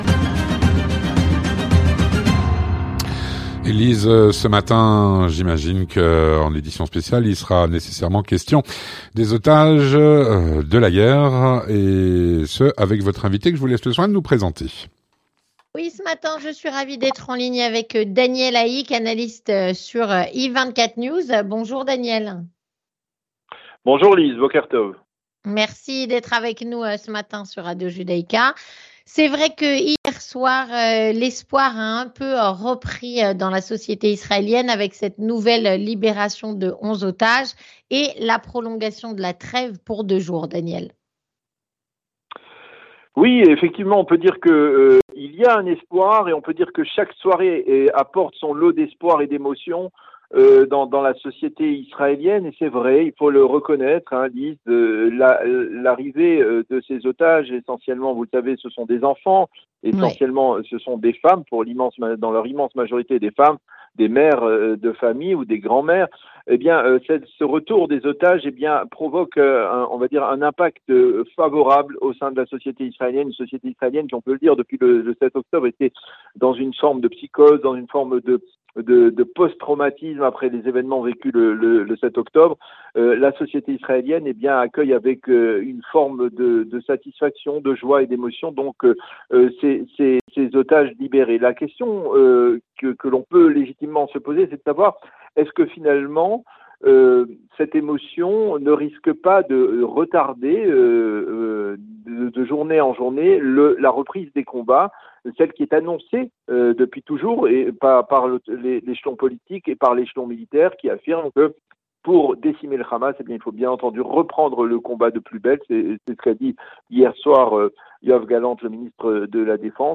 La reprise des combats après la trêve. Analyse